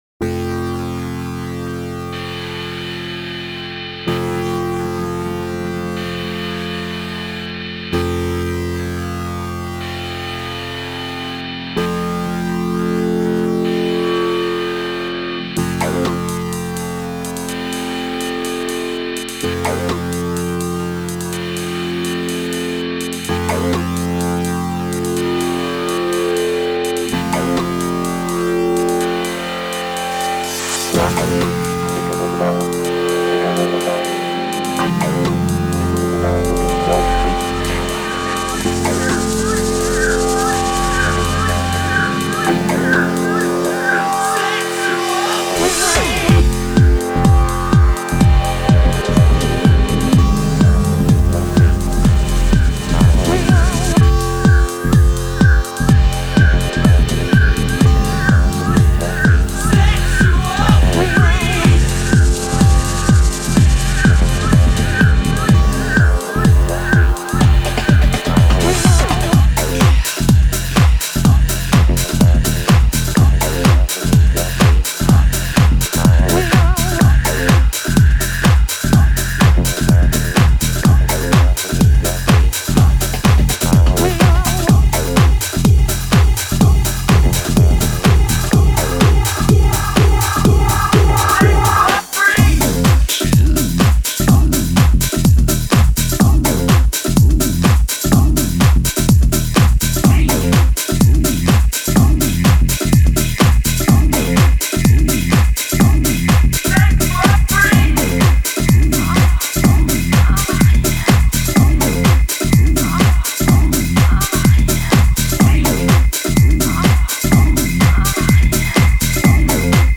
популярный британский певец.